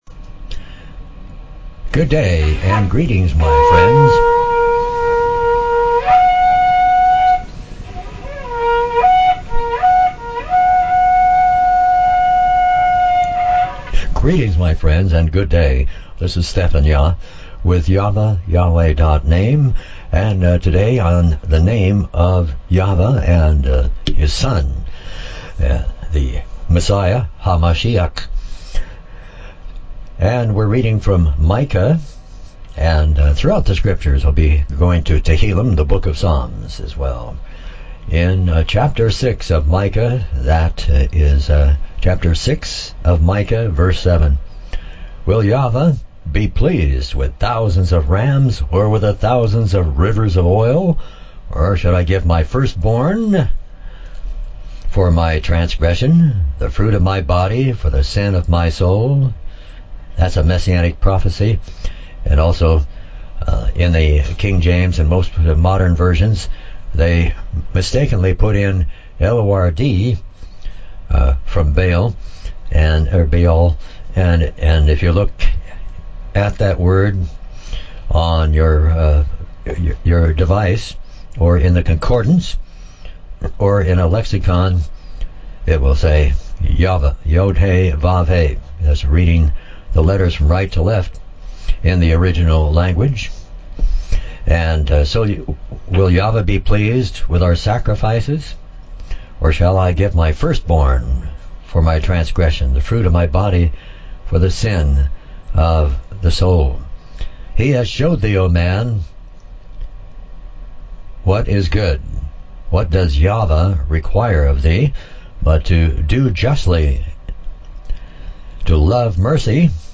SERMONS
Listen to over 200 hours of messages, teachings and presentations from Hebrew teachers on YaHVaH not Yahweh.